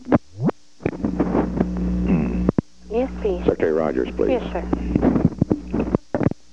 Location: White House Telephone
The President talked with the White House operator.